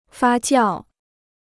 发酵 (fā jiào) Free Chinese Dictionary